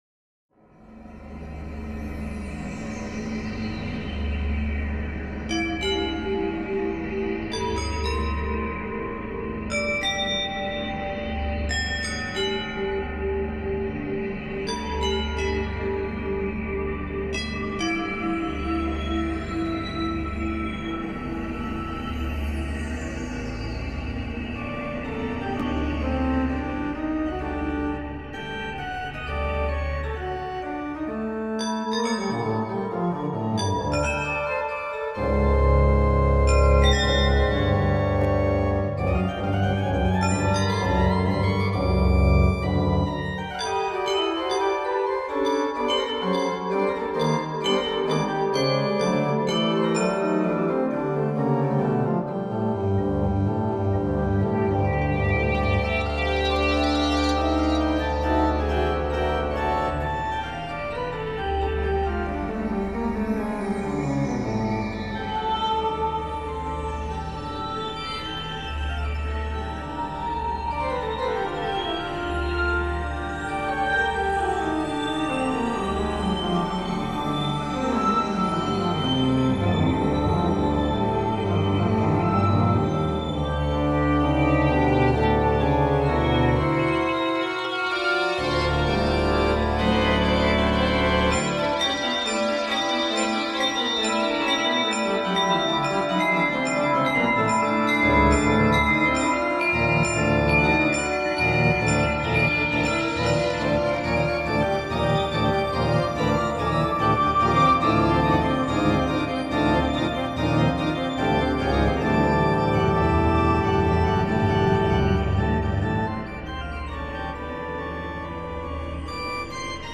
Magnuskerk Anloo sample set along with synthesizer.